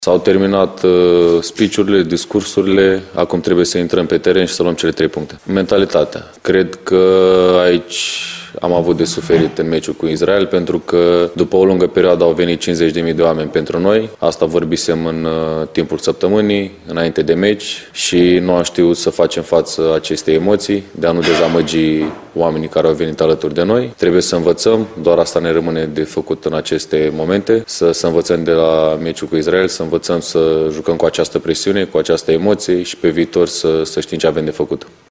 Și fundașul central al naționalei României, Andrei Burcă (30 de ani), a vorbit, în cadrul conferinței de presă premergătoare meciului cu Kosovo din preliminariile EURO 2024, despre cum felul în care fotbaliștii naționalei trebuie să se învețe să joace sub o anumită presiune.